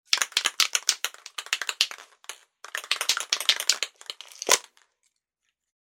Âm thanh khi Lắc hộp Sơn
Thể loại: Tiếng đồ vật
Description: Đây là âm thanh khi lắc hộp sơn. Tiếng lắc phát ra nghe lộc cộc lộc cộc cũng khá thú vi đó.
Am-thanh-khi-lac-hop-son-www_tiengdong_com.mp3